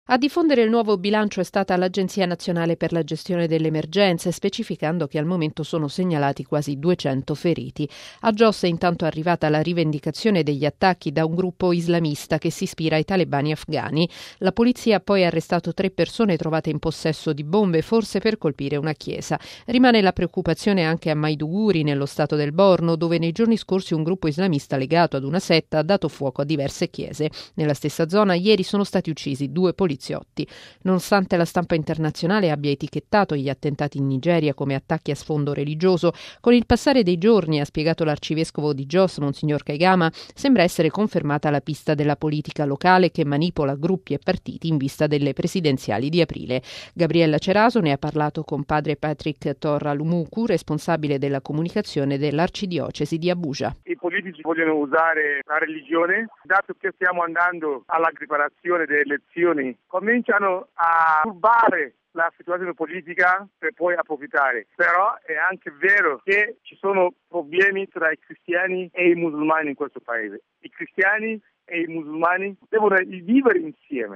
In Nigeria, è pericolosamente salito ad oltre 80 morti il bilancio delle vittime degli attentati avvenuti la vigilia di Natale a Jos - capitale dello Stato centrale del Plateau - e degli scontri che ne sono seguiti tra maggioranza musulmana e comunità cristiane. Il servizio